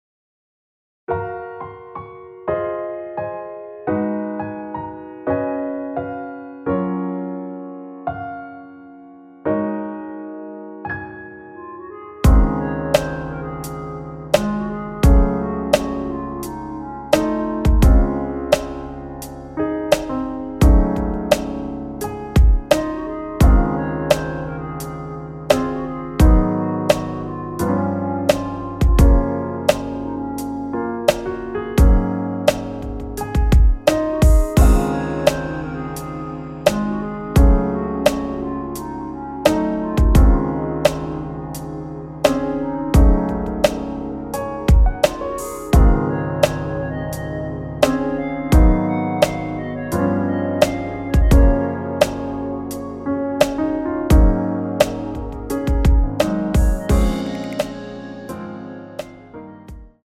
원키에서(-2)내린 멜로디 포함된 MR입니다.(미리듣기 확인)
Db
앞부분30초, 뒷부분30초씩 편집해서 올려 드리고 있습니다.
중간에 음이 끈어지고 다시 나오는 이유는